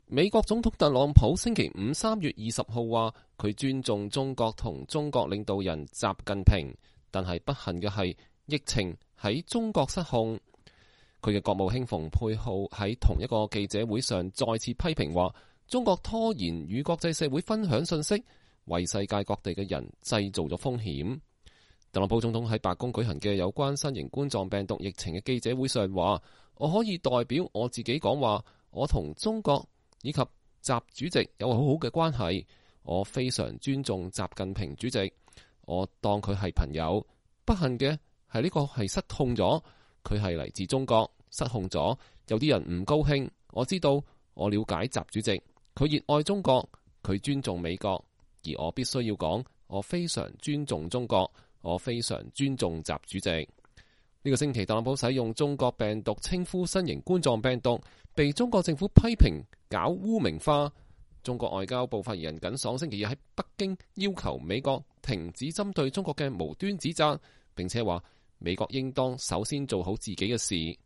特朗普在副總統彭斯、國務卿蓬佩奧、衛生部長阿扎爾、代理國土安全部長沃爾夫、新冠病毒疫情事務協調員比爾克斯和國立衛生院過敏和傳染病研究所所長弗契的簇擁下在白宮就新冠病毒疫情舉行每日記者會。(2020年3月20日)